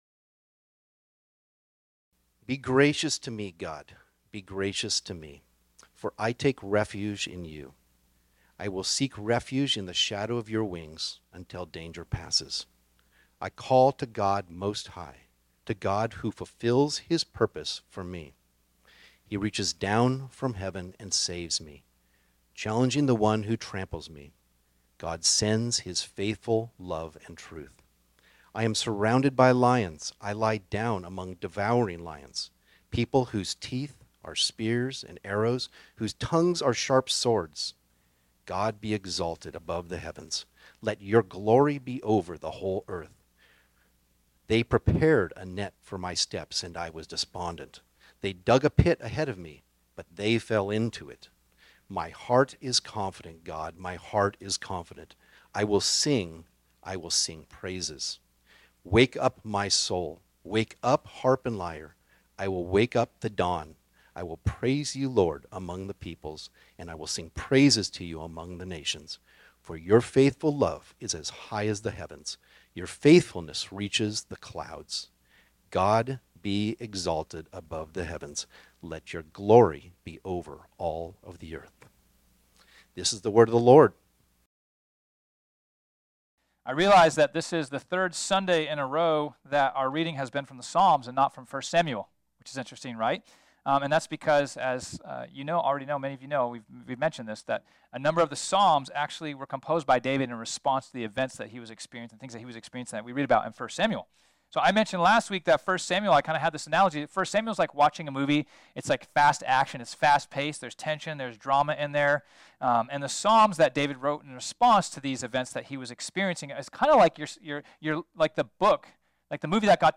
This sermon was originally preached on Sunday, June 11, 2023.